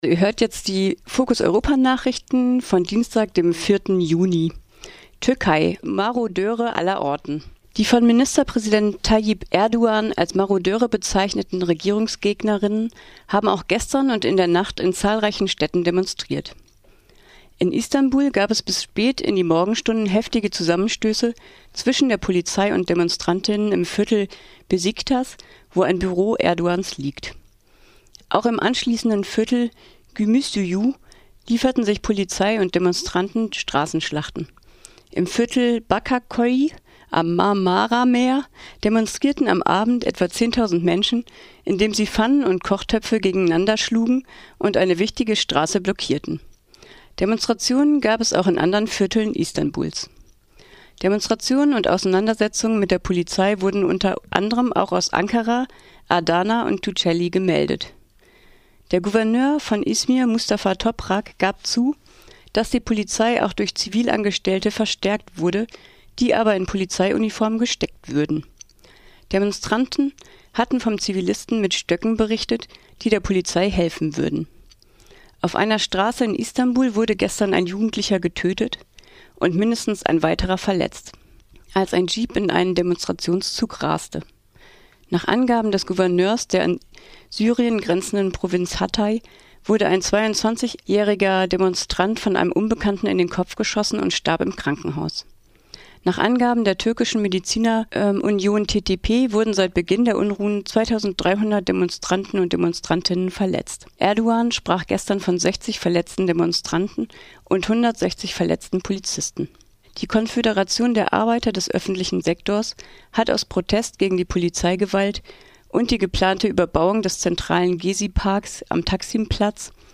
Focus Europa Nachrichten von Dienstag, den 4. Juni - 9.30 Uhr